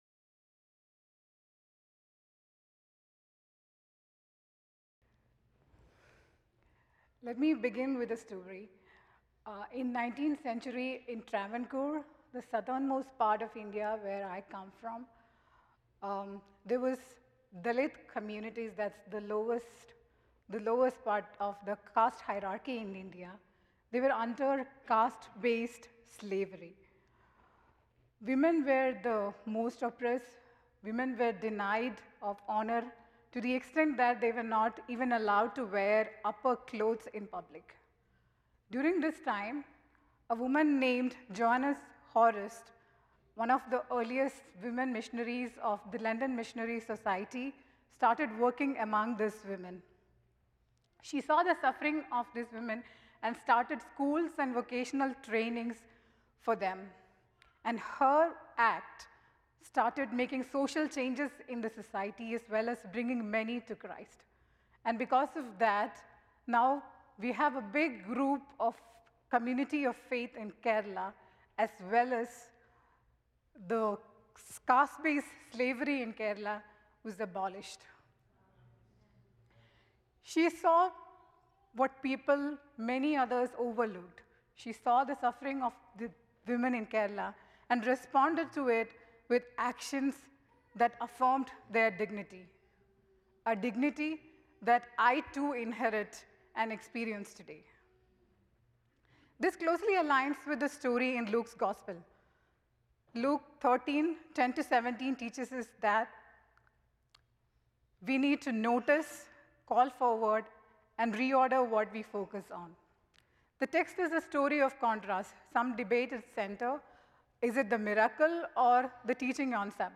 The following service took place on Wednesday, March 18, 2026.